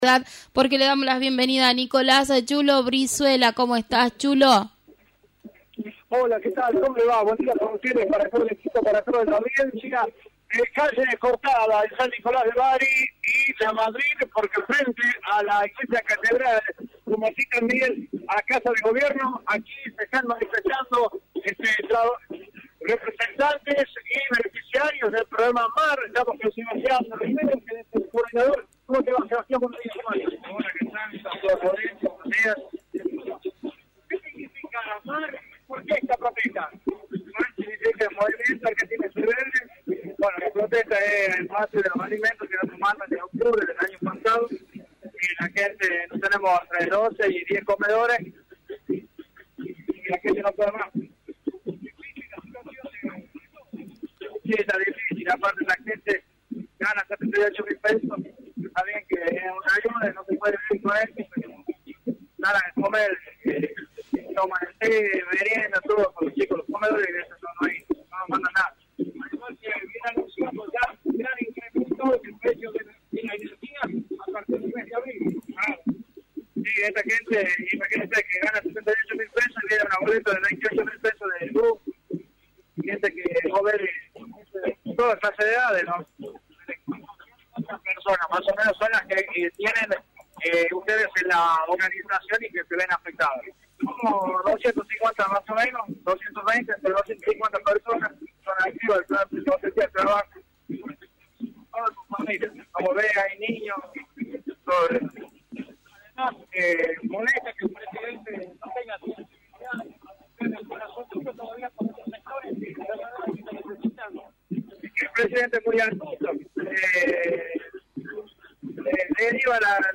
Frente  a Casa de Gobierno de La Rioja, se efectuó una masiva protesta con la presencia de representantes de comedores infantiles, por la falta de recursos